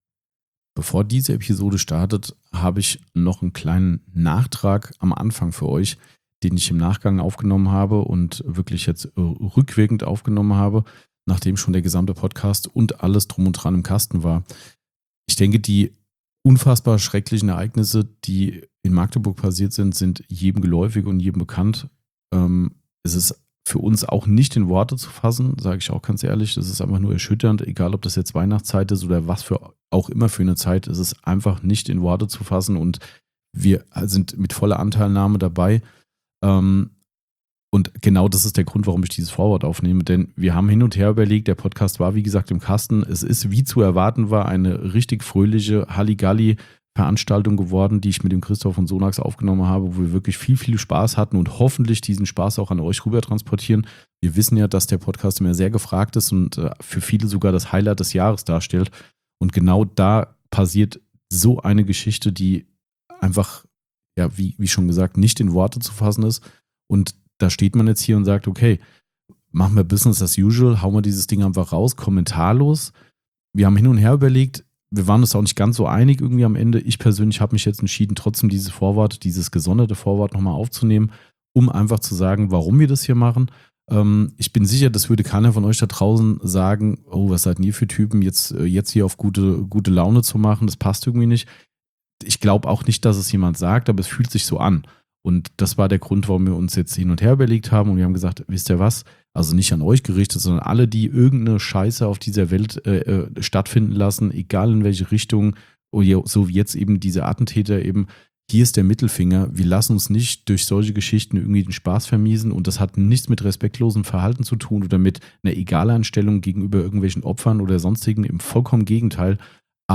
Die Detailing Gebabbel Weihnachtsfeier - Hessische Autopflege Mundart
Somit ist es, dem Anlass entsprechend, 99 % Offtopic und ein Gebabbel mit hohem Lach-Faktor. Dabei geht es um viele Themen rund um Weihnachten, vom Lieblings-Weihnachtsessen bis zu den Geschenkideen für die Familie, aber auch vollkommen andere Fragen kamen auf den Tisch. In jedem Fall war es eine große Gaudi mit hartem Hessen-Slang - aber hatten Spaß und haben sicherlich wieder die ein oder andere Zote eingebaut.